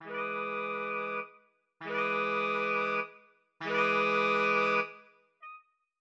\tempo 4=400
\key e \major
\set Staff.midiInstrument="soprano sax"
\set Staff.midiInstrument="alto sax"
\set Staff.midiInstrument="tenor sax"
\set Staff.midiInstrument="baritone sax"